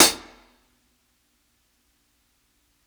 60s_HH_MED.wav